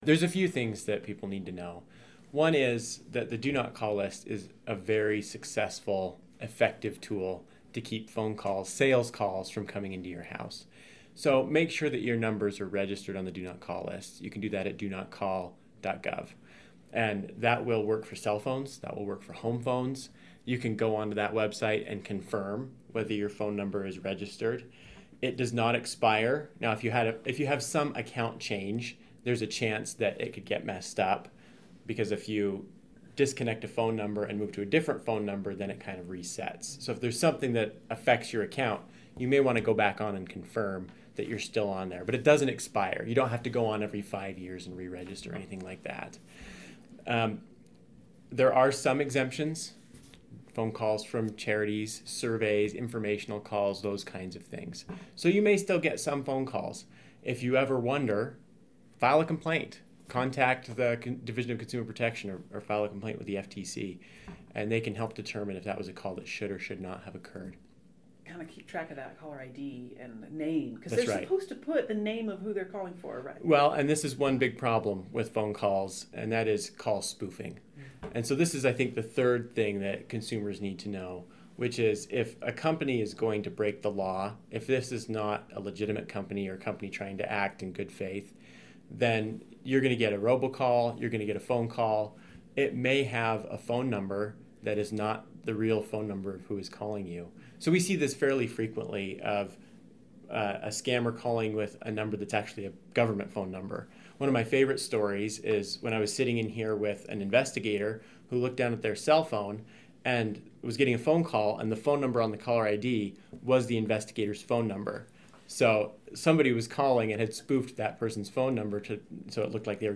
Extended interview: Robocalls, the Do Not Call list, and you